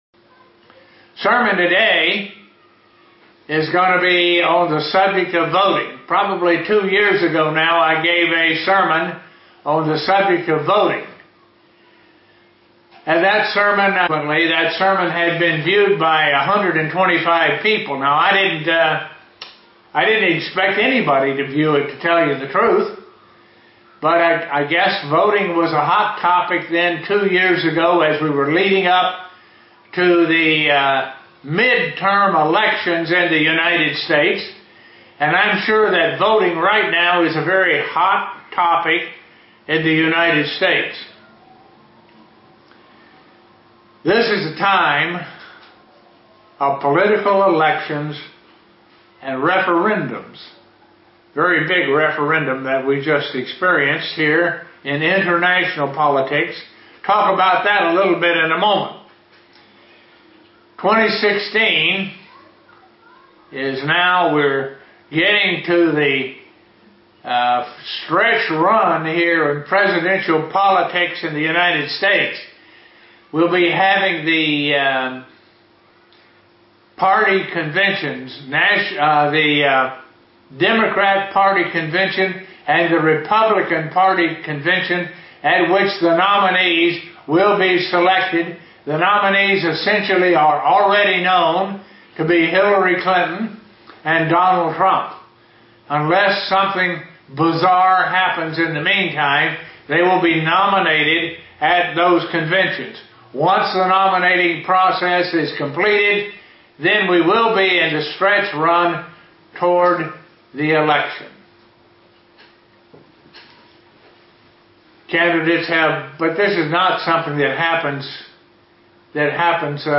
Given in Buffalo, NY
SEE VIDEO BELOW UCG Sermon Studying the bible?